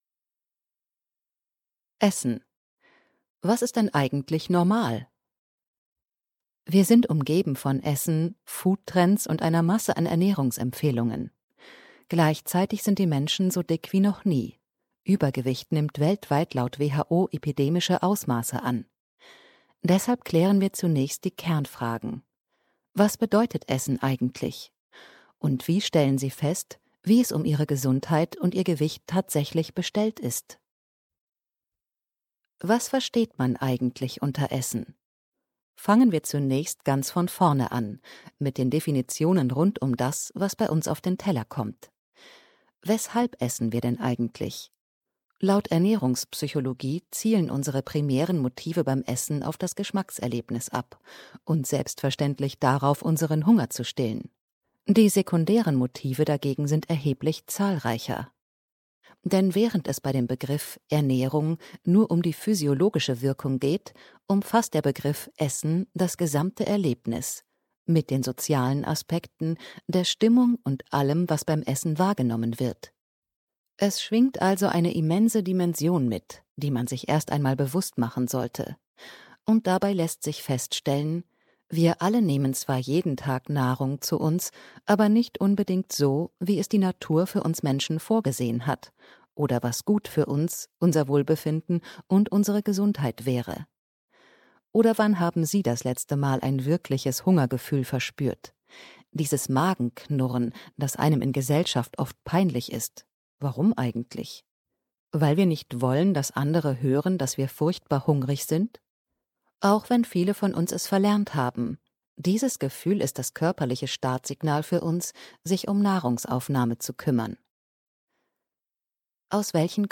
Mit Vorwort von den Autorinnen gelesen!